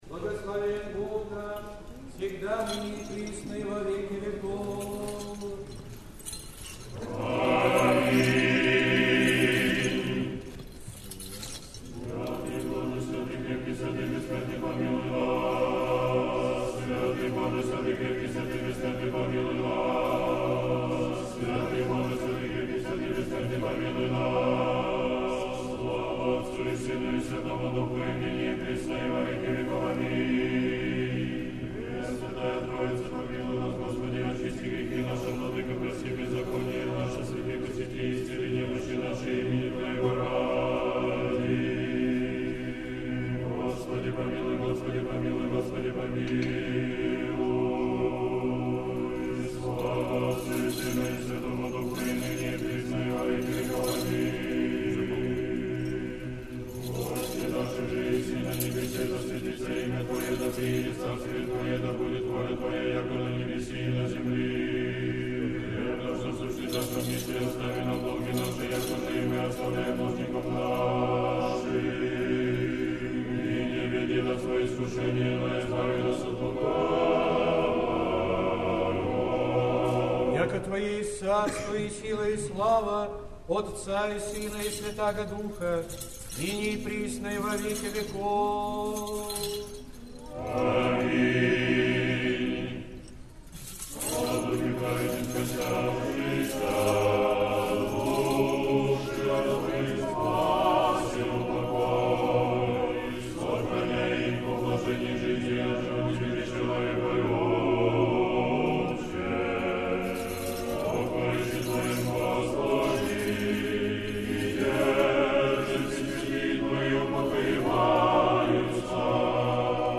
Сретенский монастырь. Заупокойная лития. Хор Сретенского монастыря.